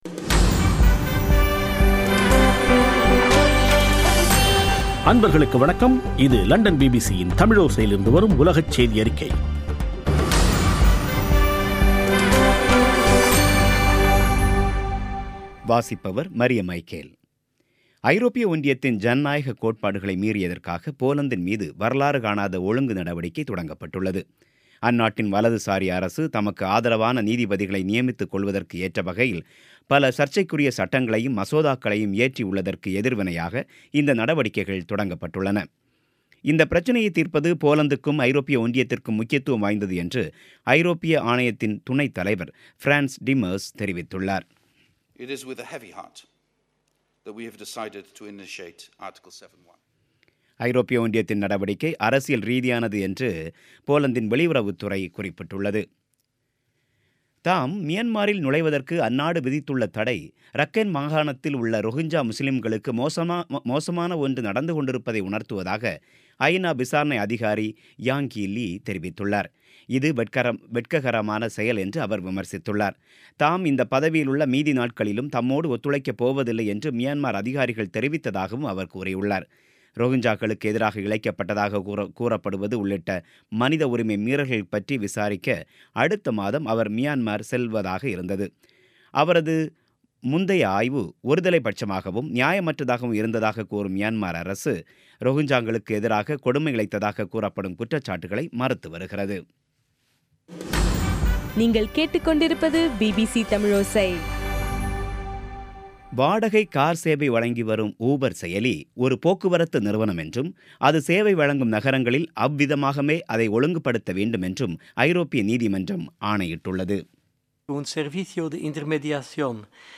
பிபிசி தமிழோசை செய்தியறிக்கை (20/12/2017)